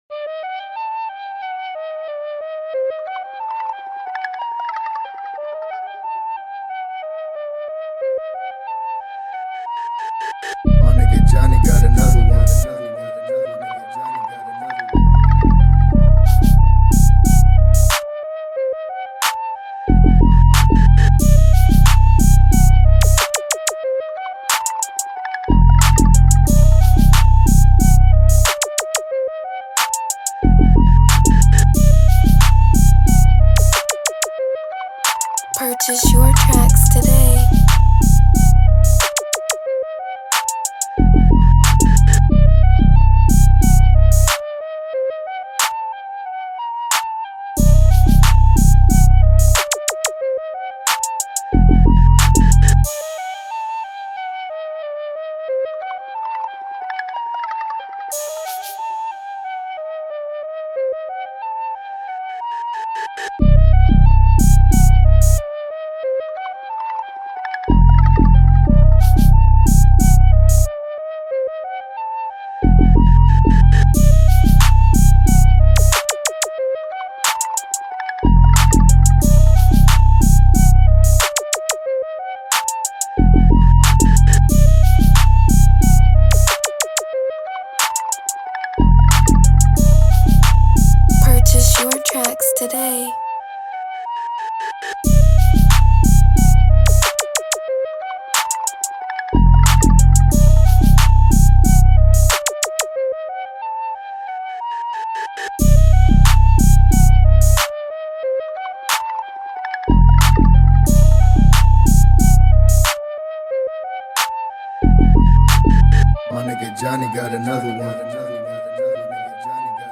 West Coast Instrumental